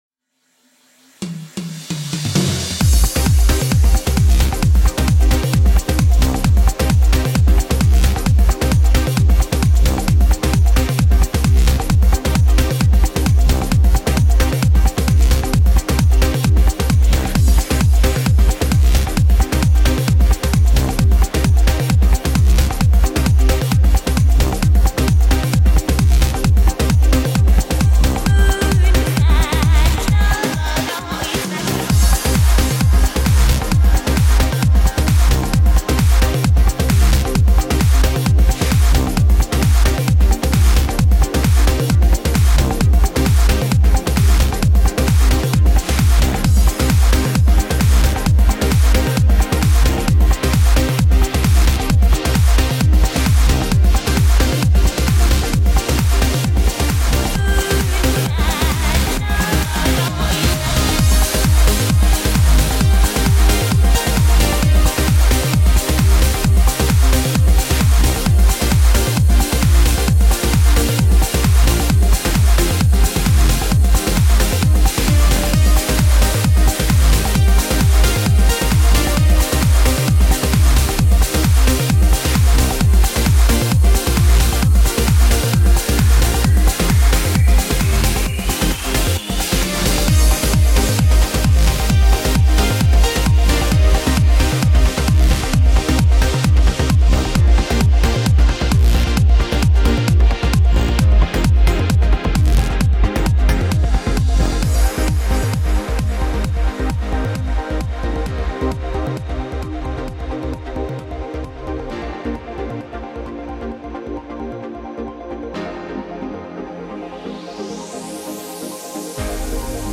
Lots of drums and percussion, TR-808-Kick bass
Piano chords in the breakdown